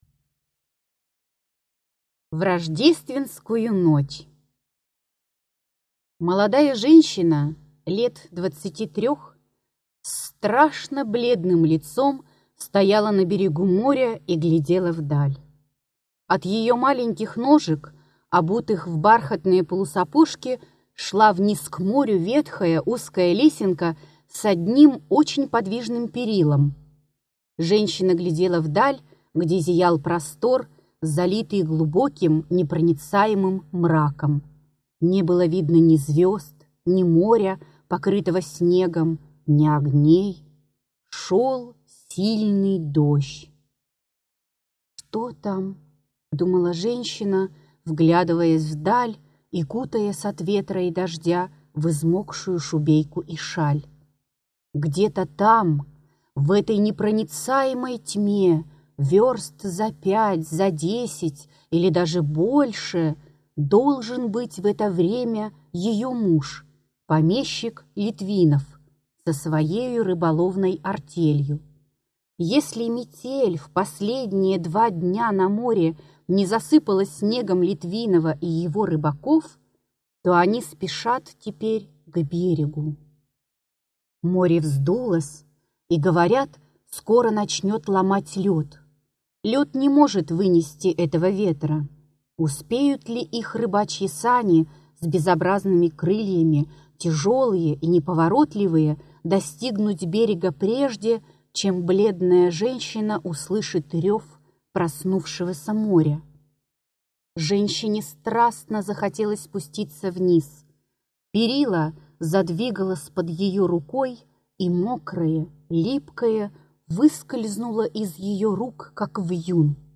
Аудиокнига Рассказы, юморески 1883 – 1884 г.г. Том 4 | Библиотека аудиокниг